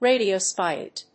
アクセント・音節ràdio・ísotope
音節ra･di･o･i･so･tope発音記号・読み方rèɪdioʊáɪsətòʊp